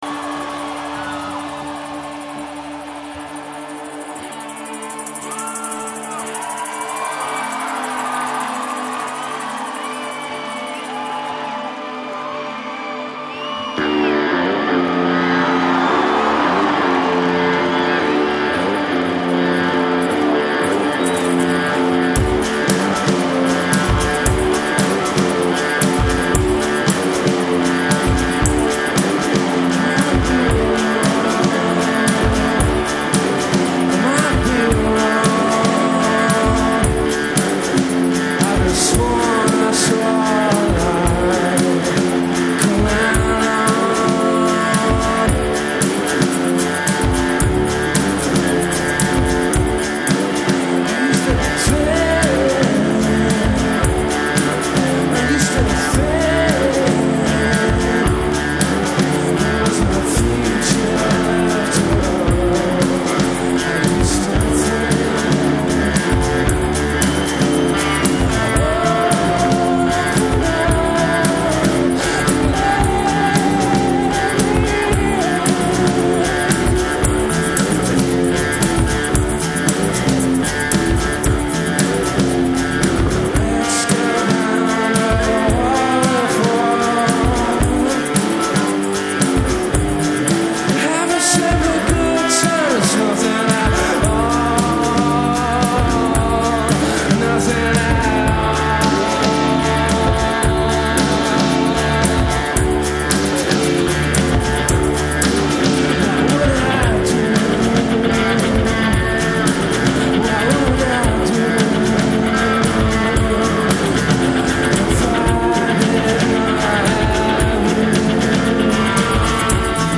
skipped note